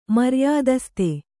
♪ maryādaste